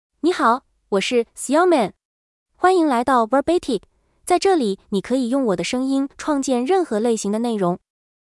Xiaomeng — Female Chinese (Mandarin, Simplified) AI Voice | TTS, Voice Cloning & Video | Verbatik AI
Xiaomeng is a female AI voice for Chinese (Mandarin, Simplified).
Voice sample
Listen to Xiaomeng's female Chinese voice.
Female
Xiaomeng delivers clear pronunciation with authentic Mandarin, Simplified Chinese intonation, making your content sound professionally produced.